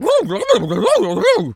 pgs/Assets/Audio/Animal_Impersonations/turkey_ostrich_gobble_03.wav at master
turkey_ostrich_gobble_03.wav